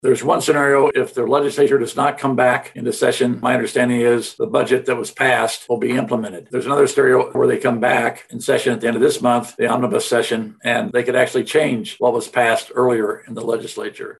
K-State administrators discussed the possibility of implementing furloughs during a virtual town hall with university faculty and staff Tuesday.